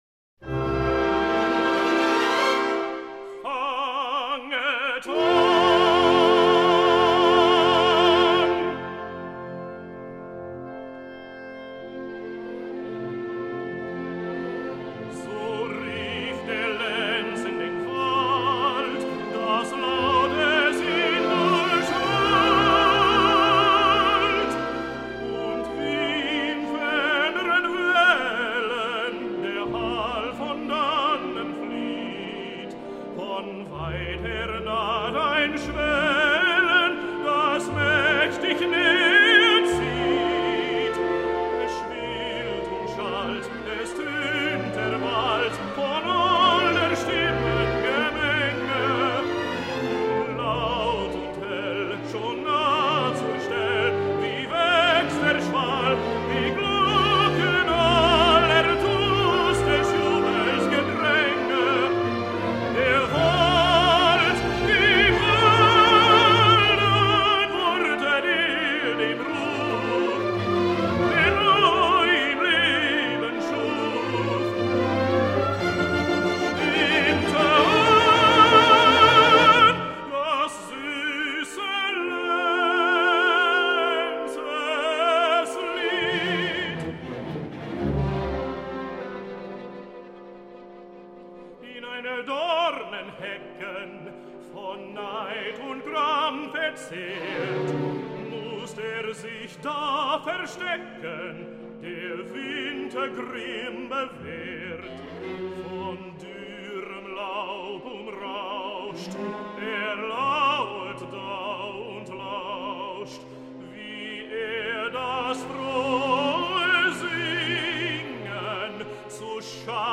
el tenor americà